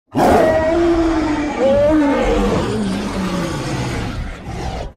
Monster Roaring Sound Effect Download: Instant Soundboard Button